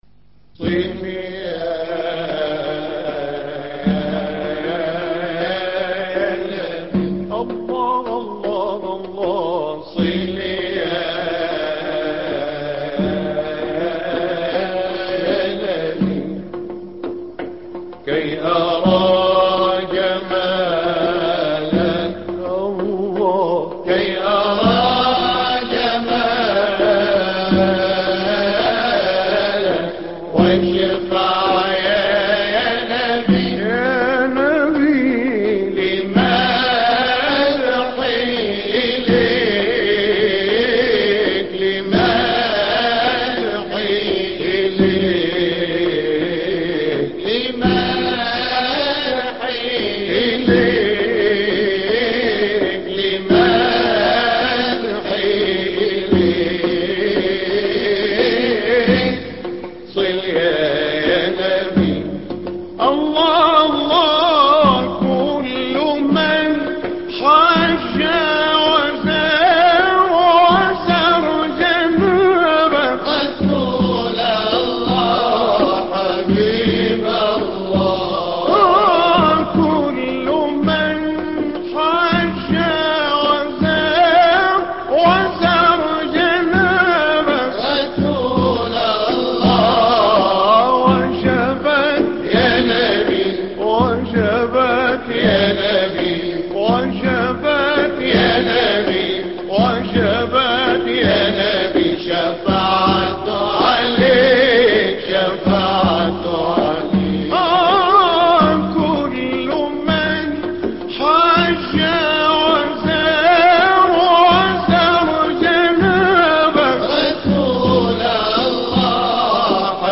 صل يانبي ـ مقام سيكا - لحفظ الملف في مجلد خاص اضغط بالزر الأيمن هنا ثم اختر (حفظ الهدف باسم - Save Target As) واختر المكان المناسب